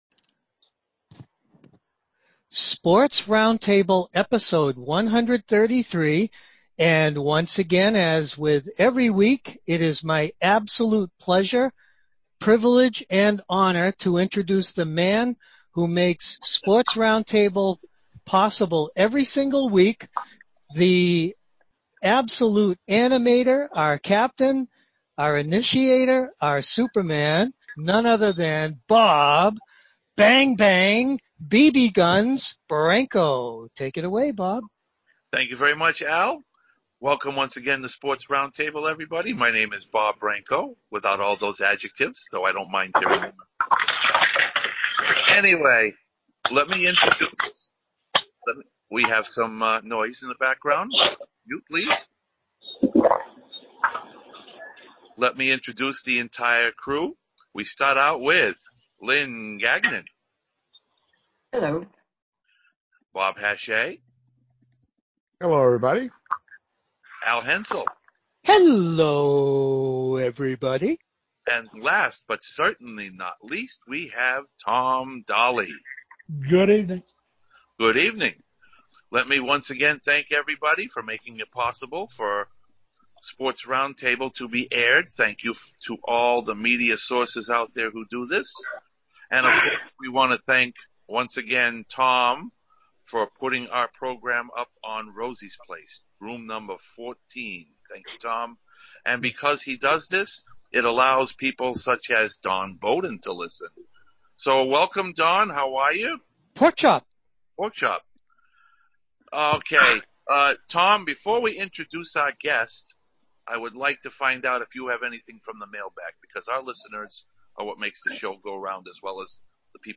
I was a guest on a Sports Roundtable podcast on May 26, 2020.
During the first half an hour, I talked about my sport of powerlifting, its basics, and my background in it. We then talked about the importance of fitness in general in the wake of the Coronavirus crisis. Then in the second half, the roundtable talked about if or when major league sports would reopen.